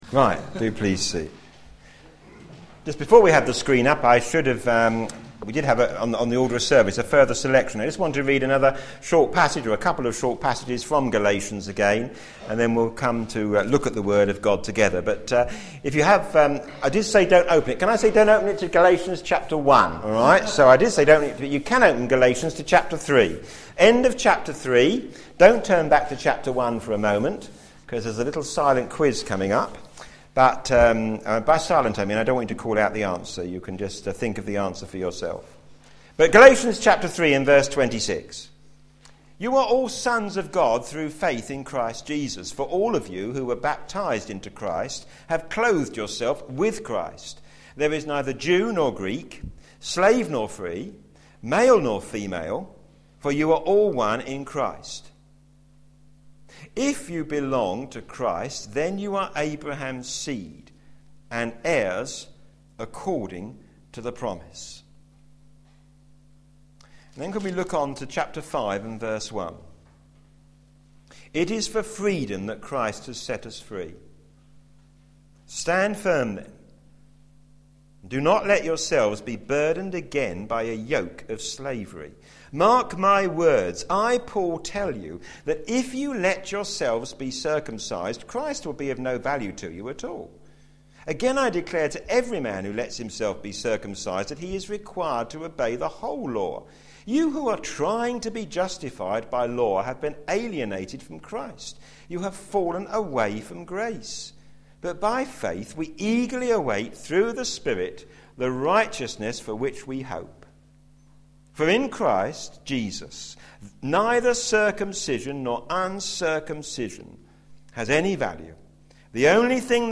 p.m. Service
Introduction & Overview Sermon